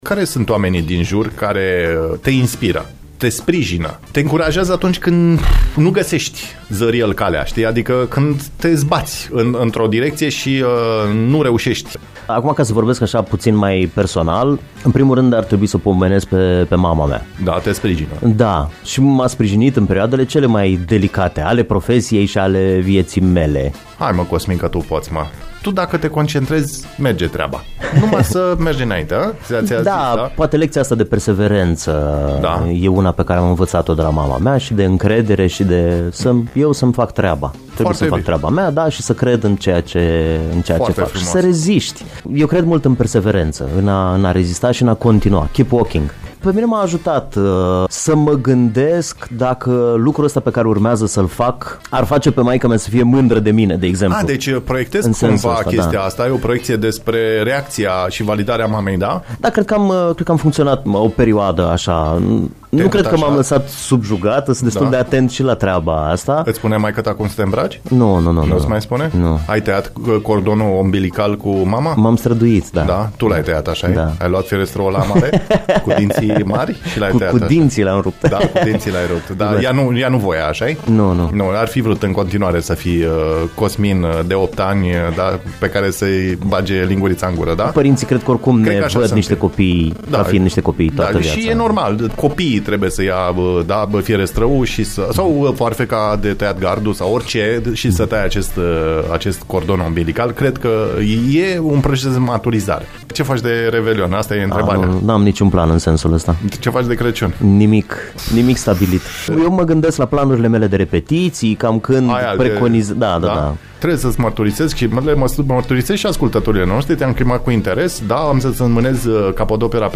Ascultați mai jos discuții incitante despre actul cultural, colaborări, pasiuni, activități conexe, echilibru psihic și fizic, relația cu publicul….